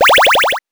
powerup_45.wav